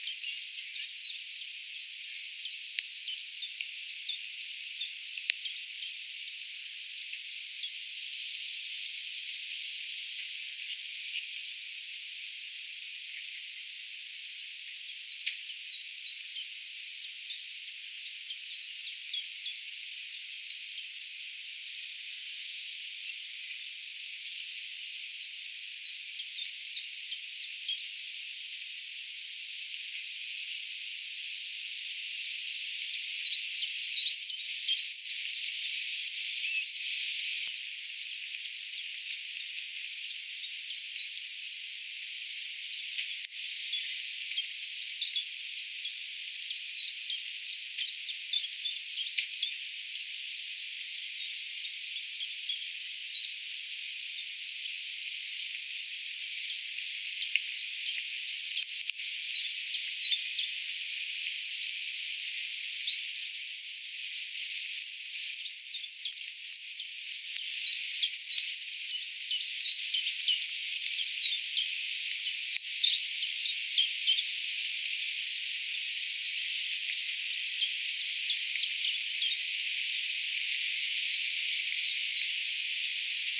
tuolla tavoin lauloi se tiltaltti
lintutornin vierellä
- ääntely muistuttaa saksalaisten tiltaltin nimeä zilpzalp (tai jotain)
- laulu on kiirehtivää ja hyppelehtivää.
tuolla_tavoin_lauloi_tiltalttilintu_lintutornin_vierella.mp3